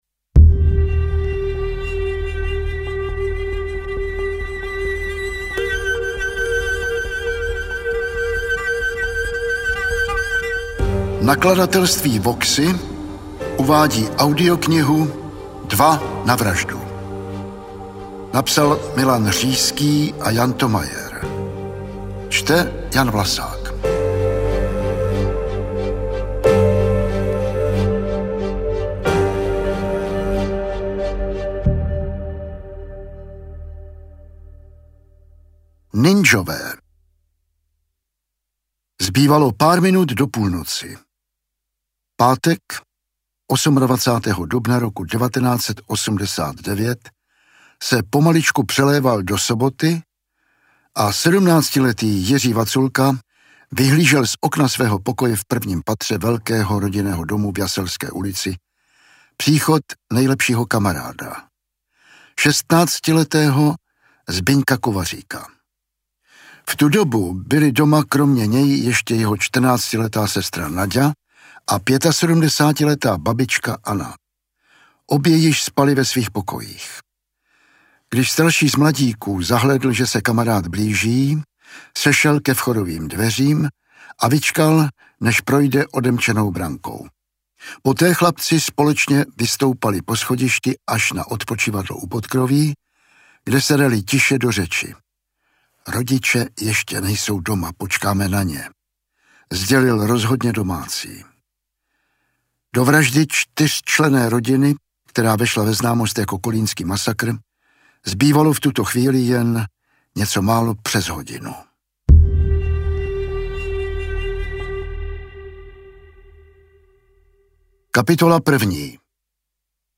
Interpret:  Jan Vlasák
AudioKniha ke stažení, 35 x mp3, délka 10 hod. 50 min., velikost 591,7 MB, česky